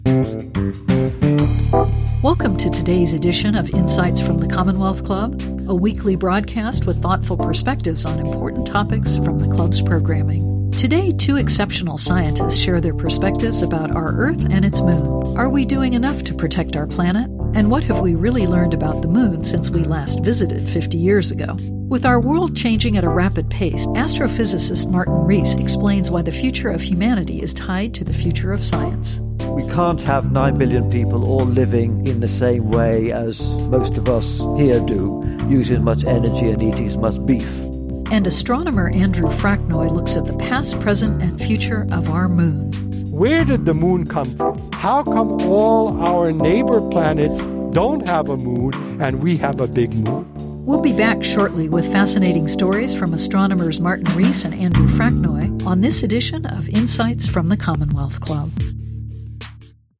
Ep003 Segment A. 1-min Billboard